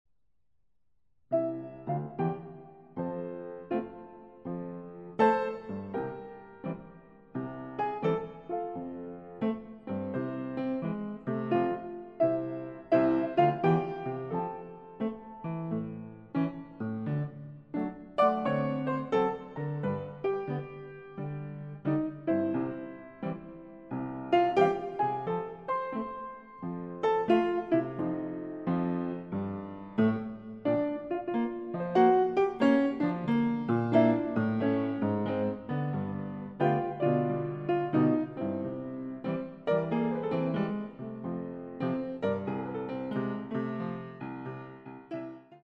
Style: New Orleans Piano